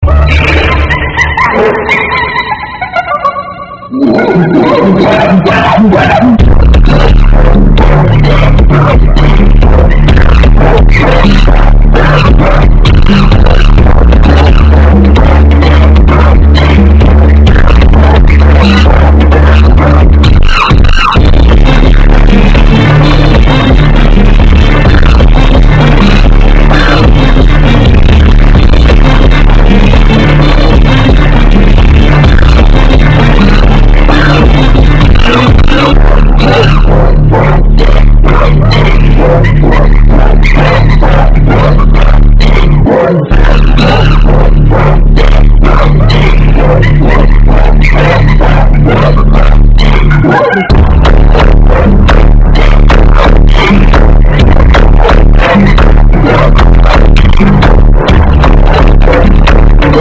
makaka funk Meme Sound Effect